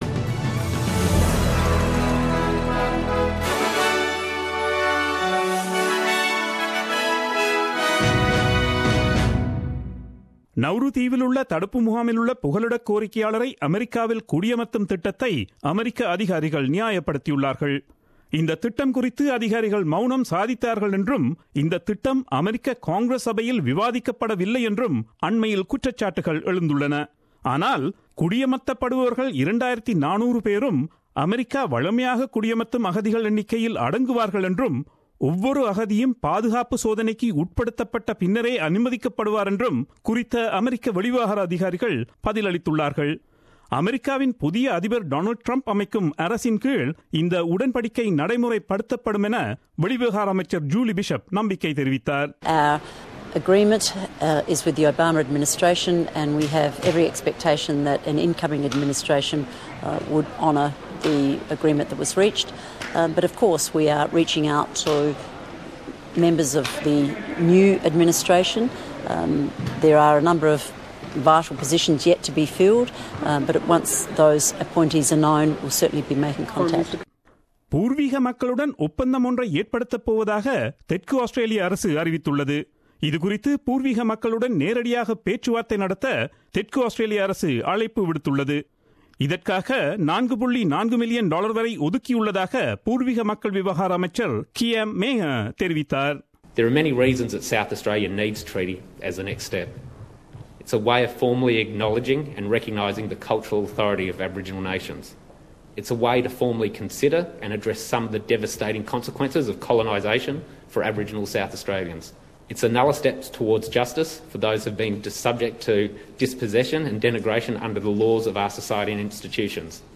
Australian news bulletin aired on Wednesday 14 Dec 2016 at 8pm.